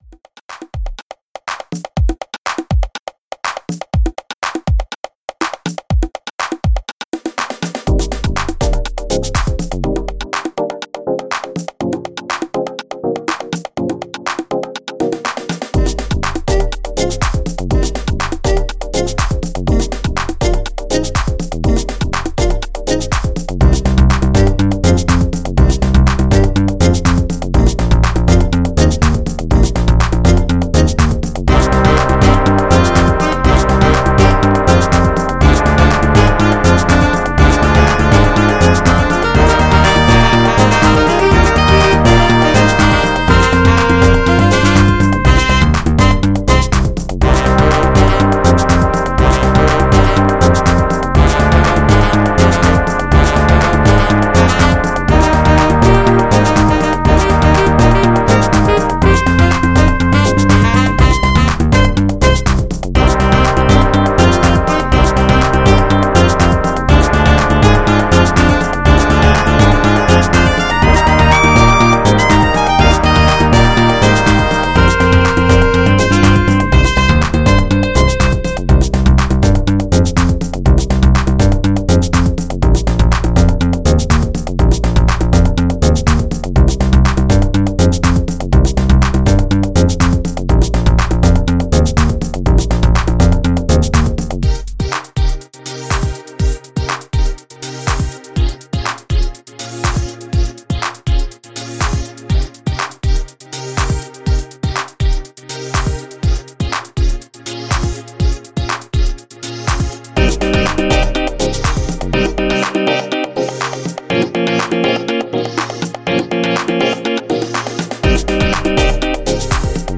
alx-049-jazz-saxophone.mp3